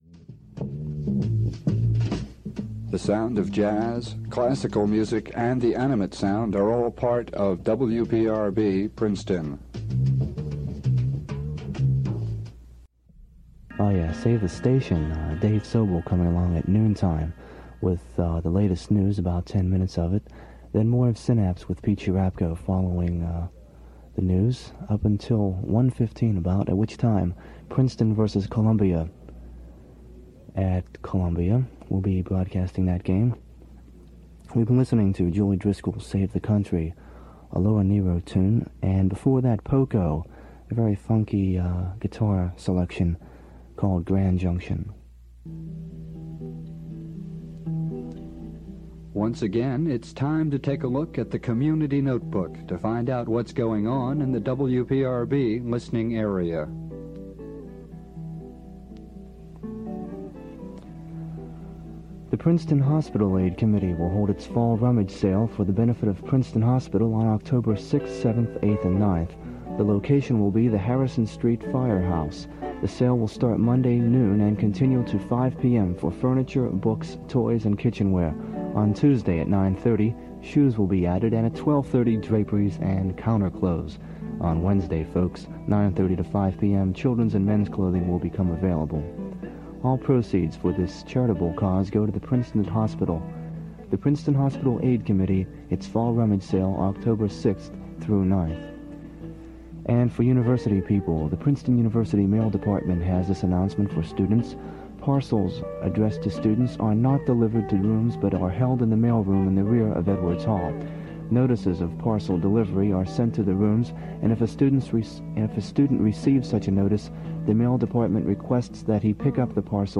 All selections sourced from the October 4th, 1969 Aircheck. WPRB, Princeton.
Mic break / backannounce #2
(“Animate Sound” bumpers, Princeton vs. Columbia forward promote, upcoming community events, University mail procedure announcement for students, break to music by Hendrix, Creedence, Janis Joplin, Judy Collins.)